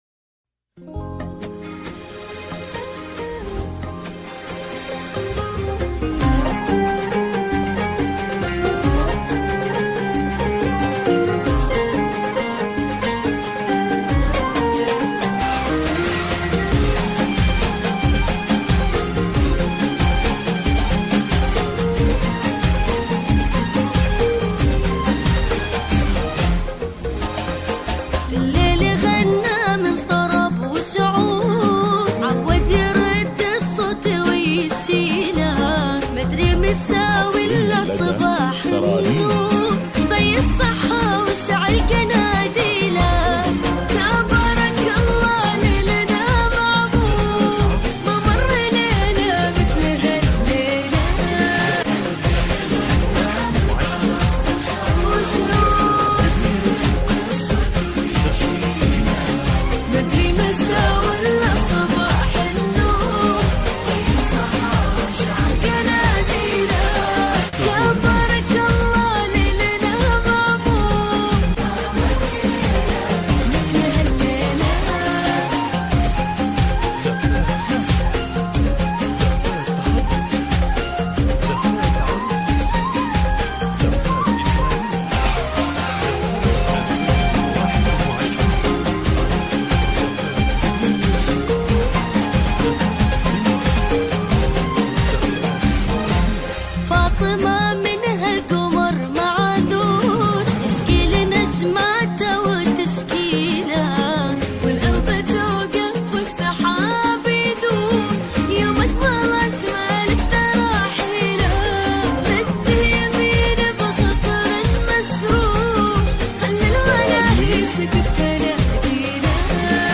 مسار زفات